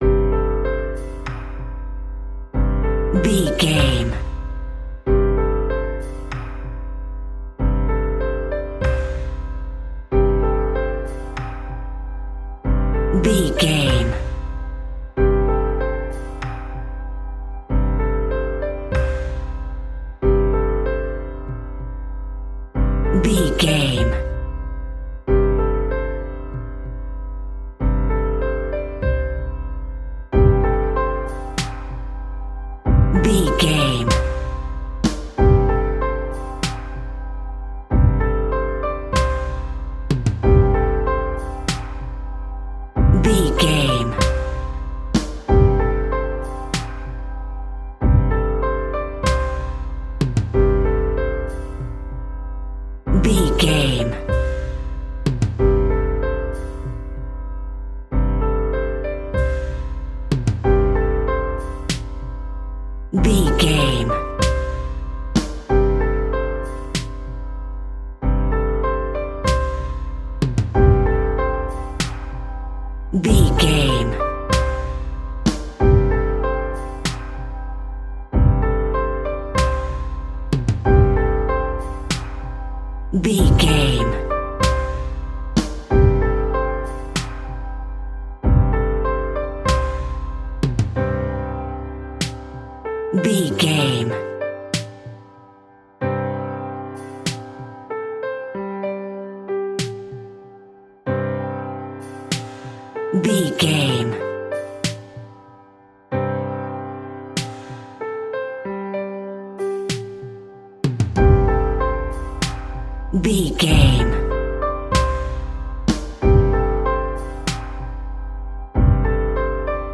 Ionian/Major
hip hop
chilled
laid back
hip hop drums
hip hop synths
piano
hip hop pads